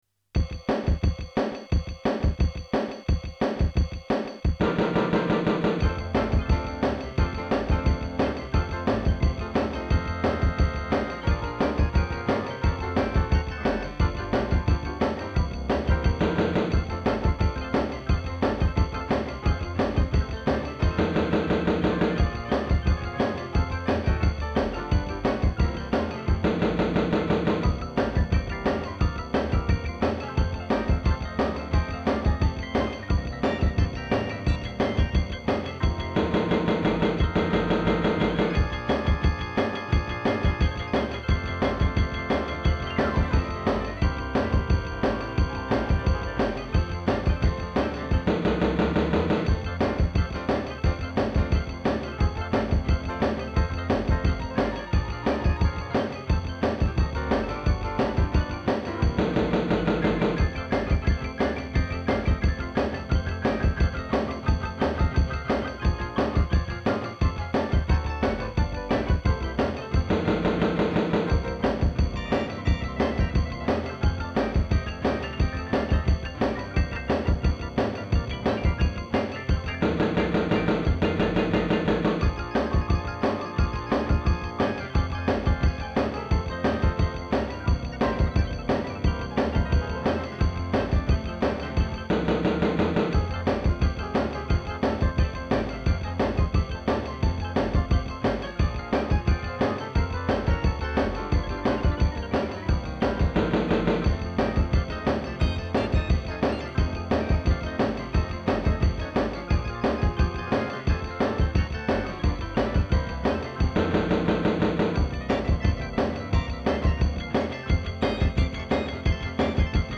(4 МБ, стерео)